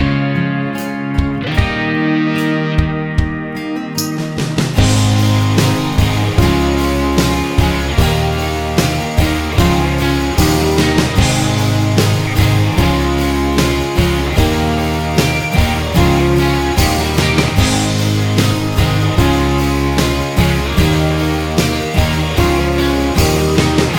no Backing Vocals Country (Female) 4:13 Buy £1.50